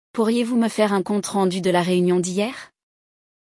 Nosso podcast é 99% em francês e te coloca em contato direto com o idioma por meio de diálogos naturais e situações reais do cotidiano.
Você vai ouvir uma conversa entre falantes nativos, identificar expressões novas e, com a orientação da professora, repetir as frases para melhorar sua pronúncia.